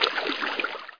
SwimFreestyle2.mp3